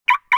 chirpTwice.wav